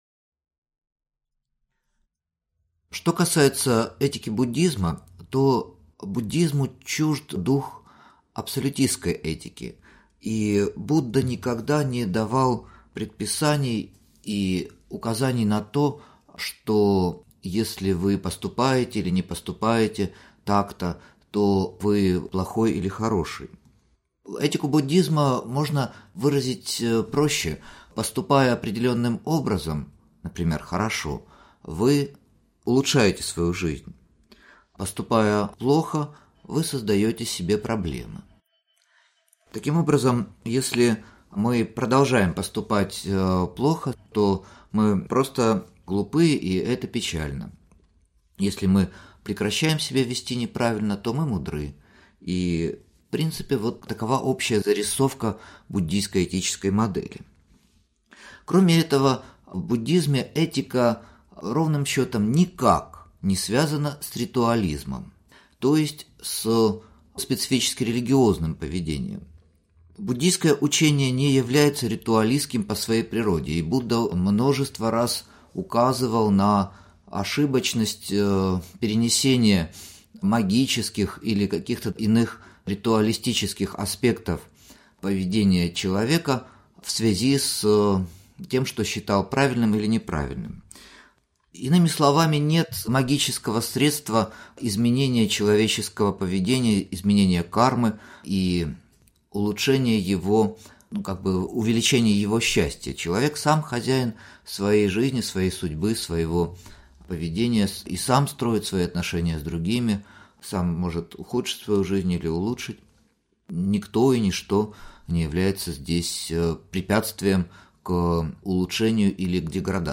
Аудиокнига Буддийская этика | Библиотека аудиокниг
Прослушать и бесплатно скачать фрагмент аудиокниги